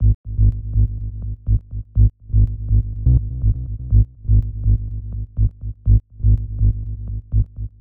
• tech house bass samples 2 - G#m - 123.wav
tech_house_bass_samples_2_-_G_sharp_m_-_123_sKv.wav